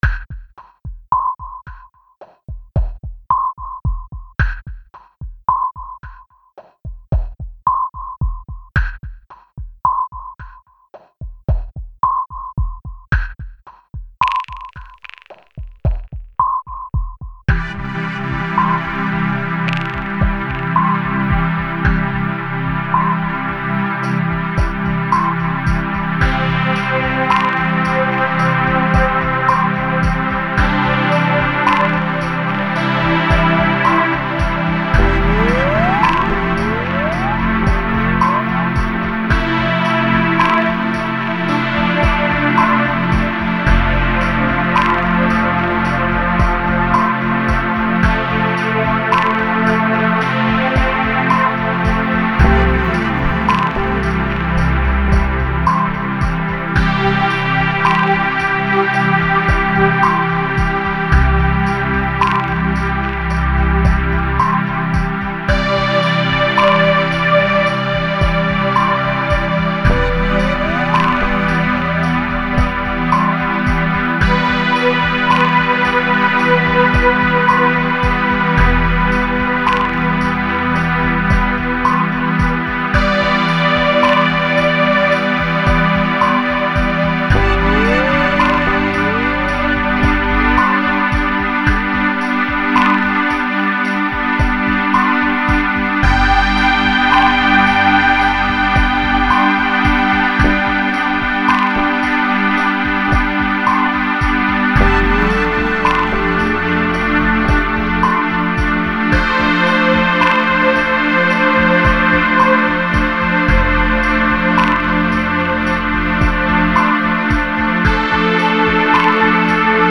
Genre: Ambient,Electronic.